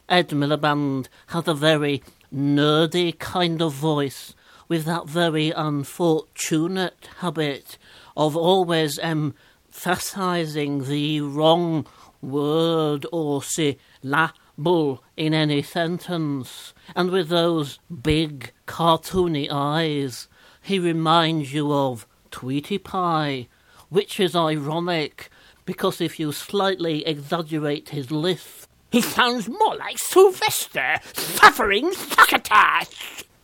Steve Nallon's impression of Ed Miliband